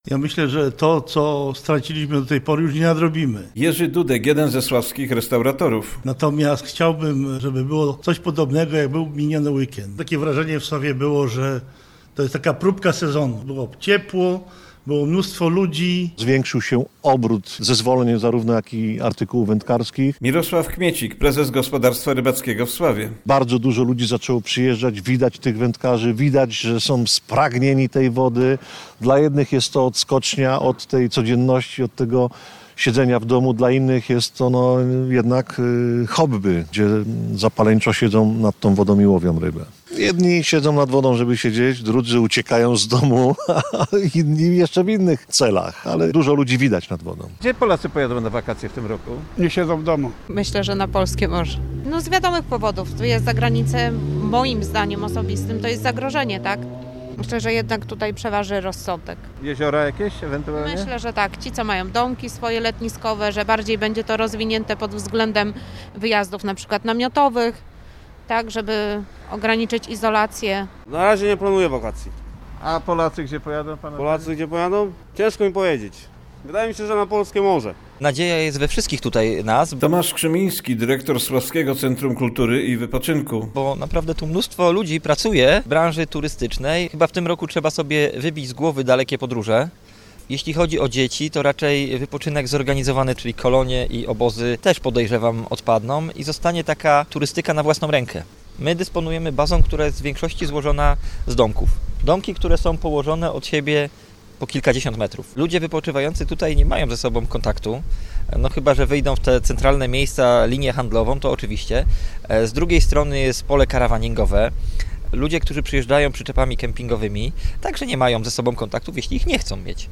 Zastanawiają się jednak jakie miejsca na wakacje wybiorą w tym roku Polacy. Relacja